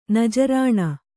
♪ najarāṇa